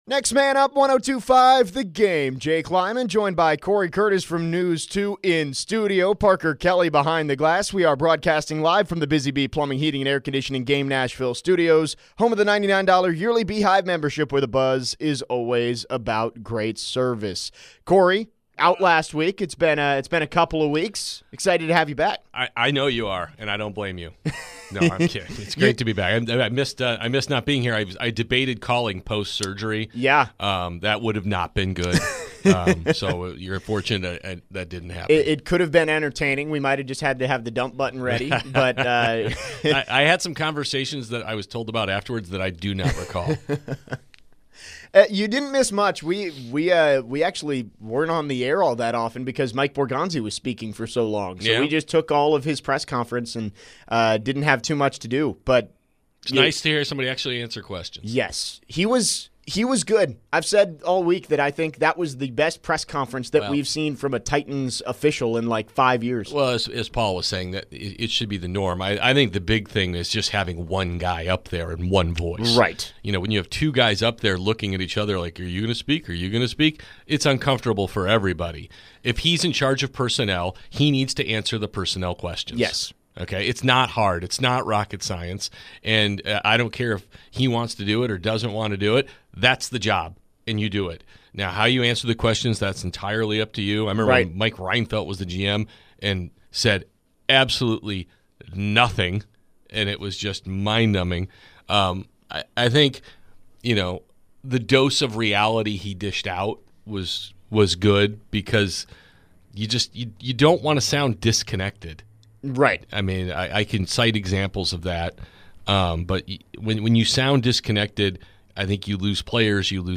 in studio today. They discuss how the Tennessee Titans should approach the rest of the season and other NFL headlines.